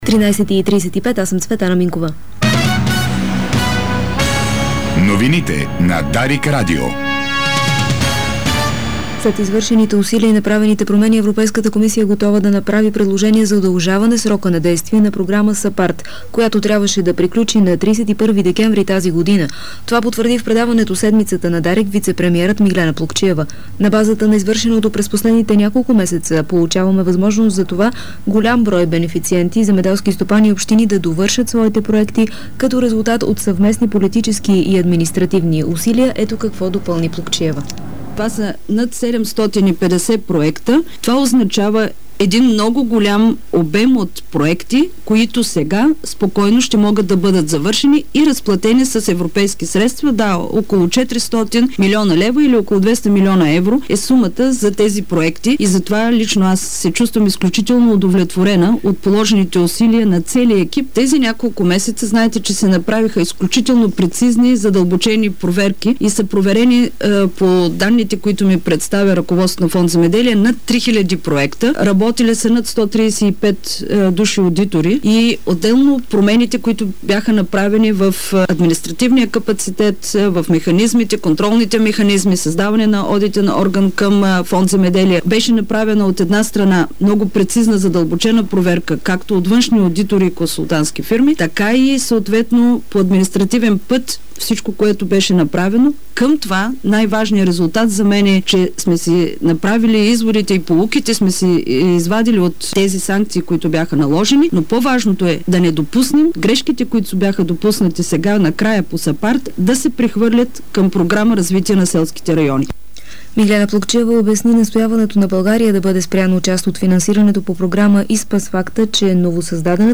Обедна информационна емисия - 08.11.2008